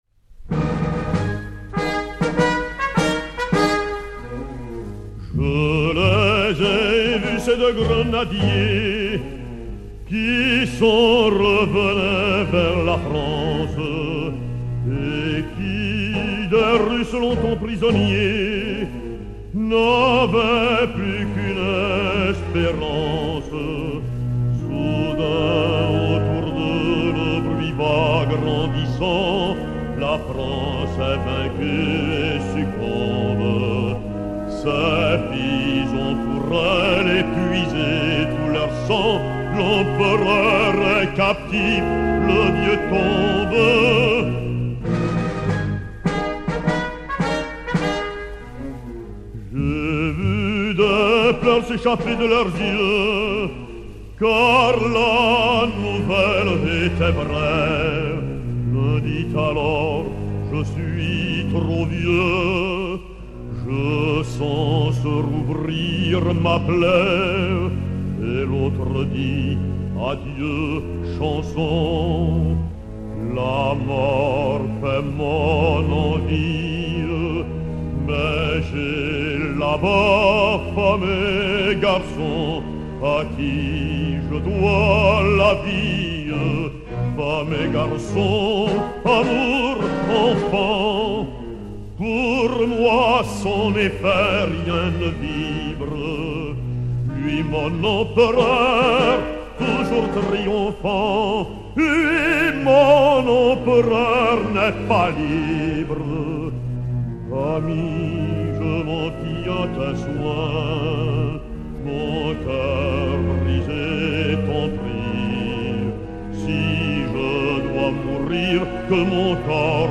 baryton-basse français